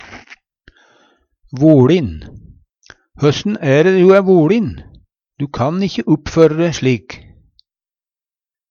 voLin - Numedalsmål (en-US)